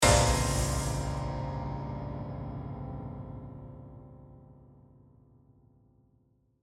Download Free Horror Sound Effects | Gfx Sounds
Horror-piano-chord-crash-piano-lid-haunted.mp3